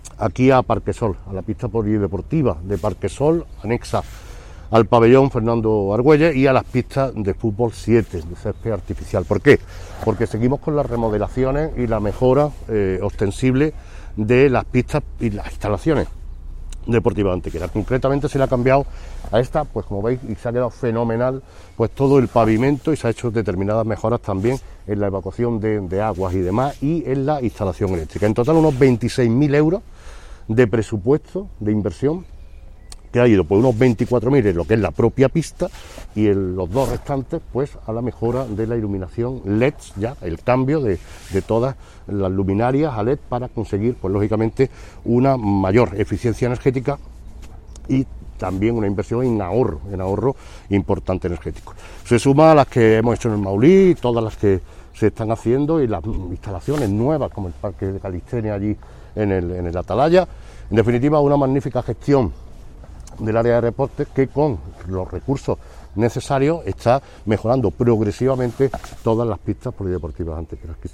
El alcalde de Antequera, Manolo Barón, y el teniente de alcalde delegado de Deportes, Juan Rosas, han informado en rueda de prensa sobre la conclusión de los trabajos desarrollados para la mejora integral de la pista polideportiva de Parquesol, anexa al pabellón Fernando Argüelles y a los campos de fútbol 7.
Cortes de voz